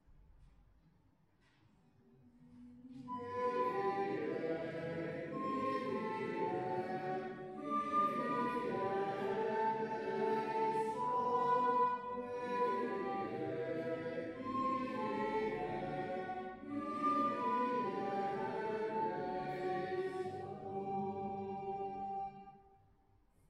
Pregària de Taizé a Mataró... des de febrer de 2001
Parròquia de la Sagrada Família - Diumenge 26 de juny de 2016
Vàrem cantar...